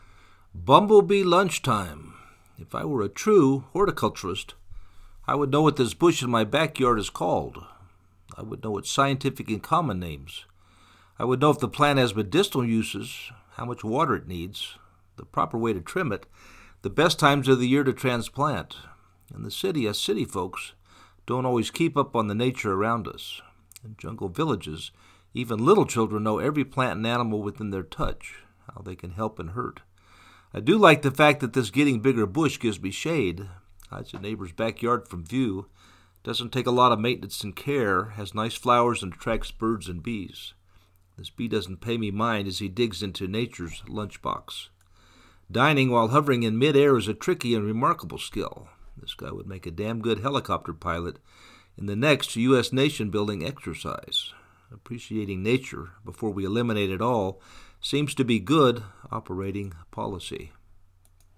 Bumblebee Lunchtime Backyard dining
This bee doesn’t pay me mind as he digs into nature’s lunchbox. Dining, while hovering in mid air, is a tricky and remarkable skill.
bumblebee-lunchtime-4.mp3